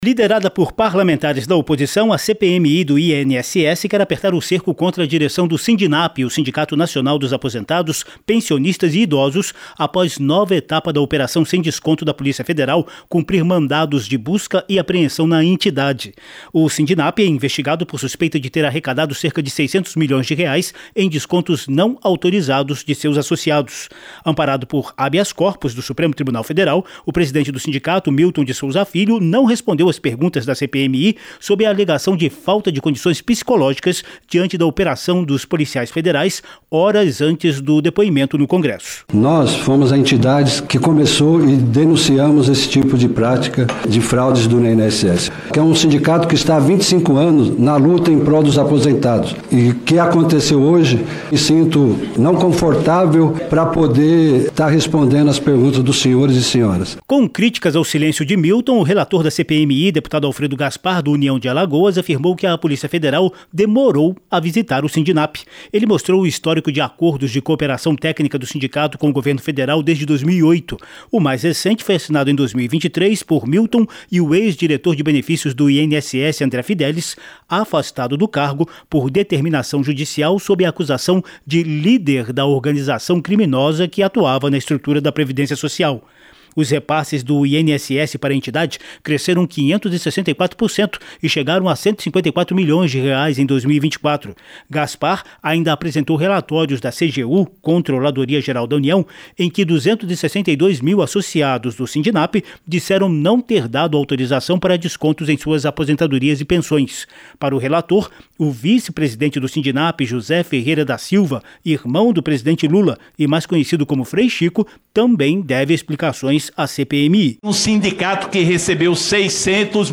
CPMI do INSS mira na direção do Sindnapi em meio a nova operação da PF - Radioagência